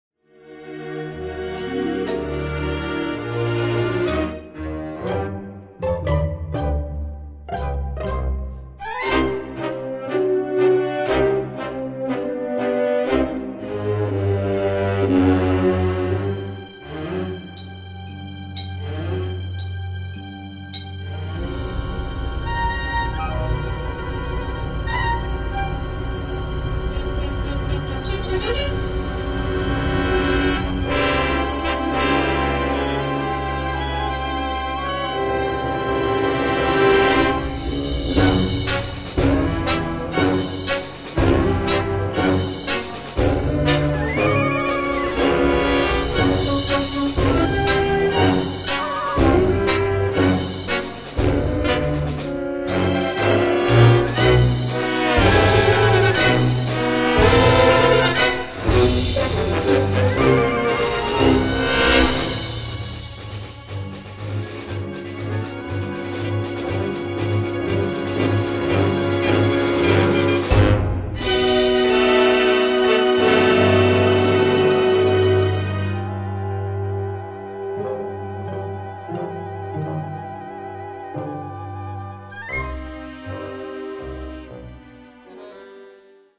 Orchestral: